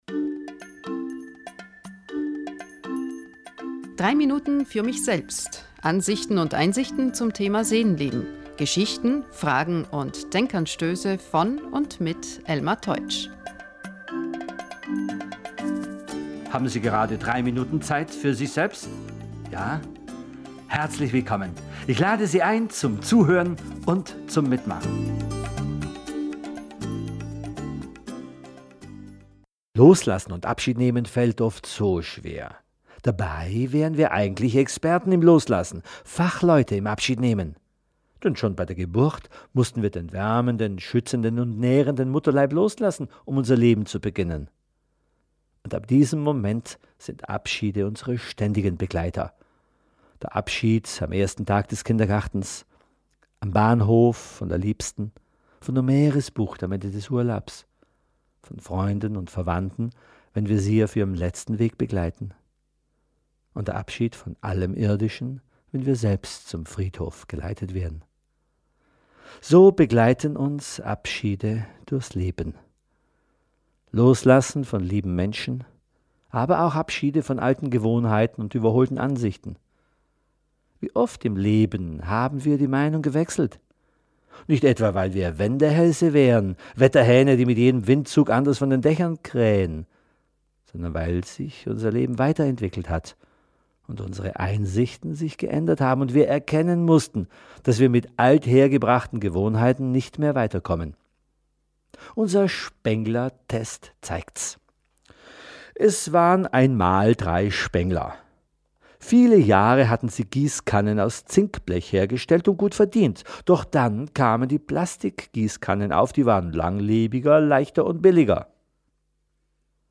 79-3Minuten-Zusa--tze-Loslassen_-Der-Spengler-Test-mit-An-und-Abmoderation-mp3.mp3